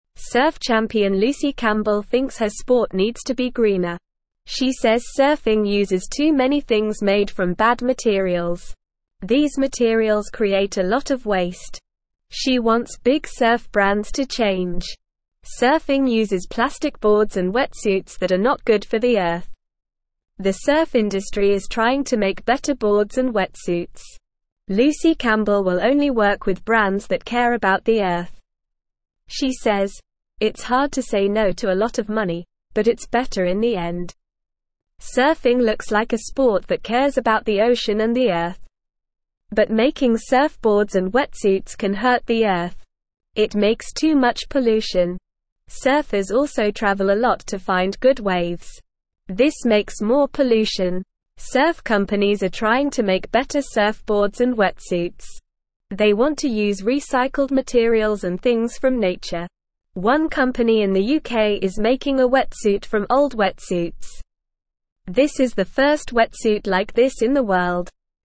Normal
English-Newsroom-Beginner-NORMAL-Reading-Surf-Champion-Wants-Greener-Sport.mp3